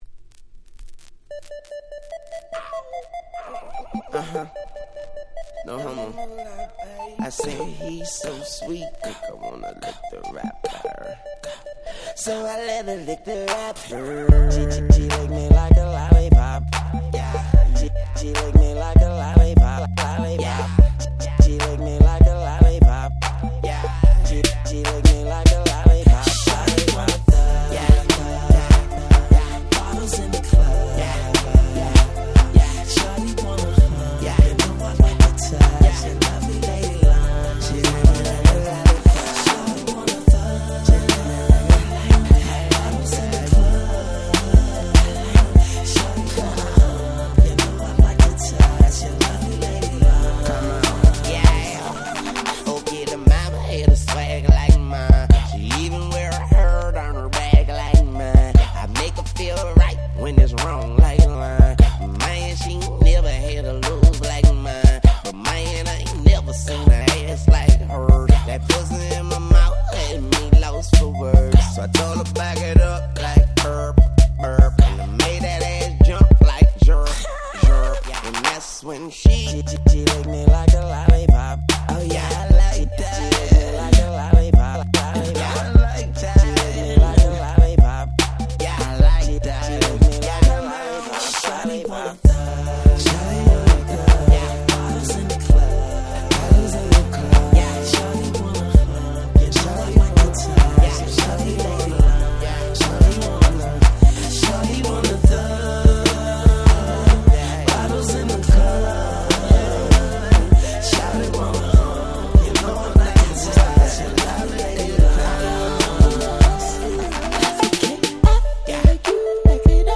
08' Super Hit Hip Hop !!